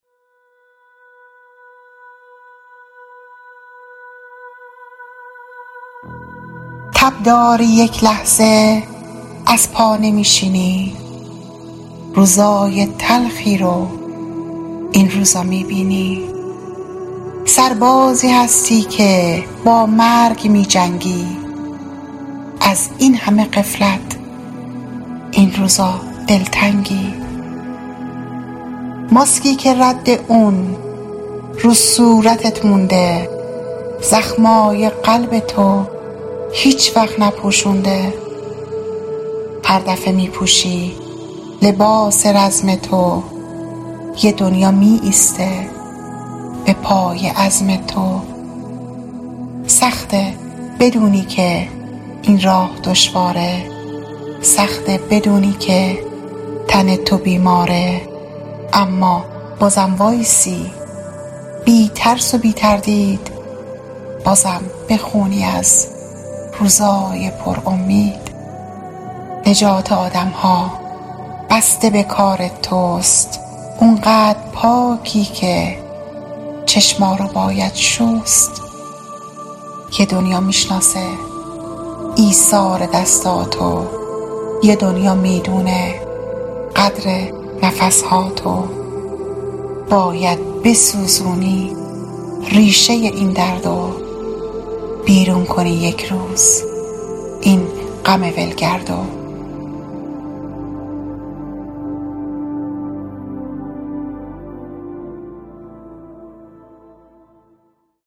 دانلود نسخه صوتی دکلمه دانلود / گوینده: